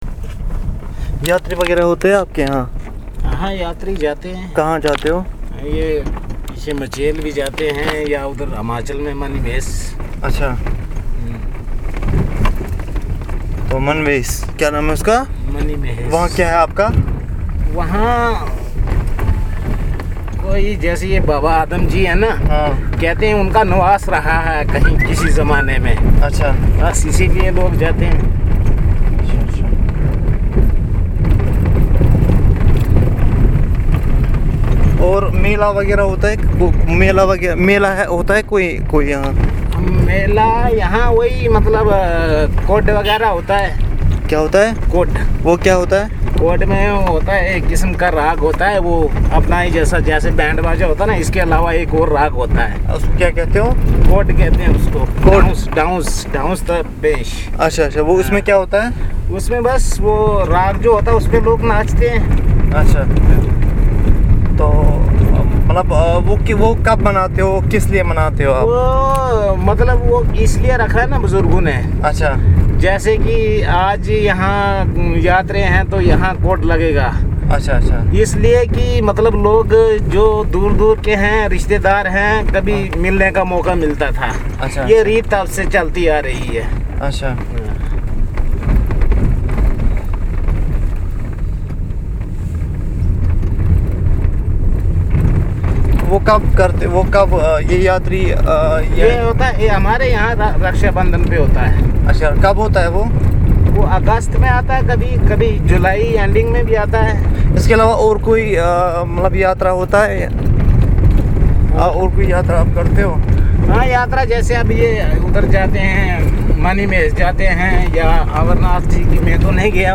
Conversation on multi-domain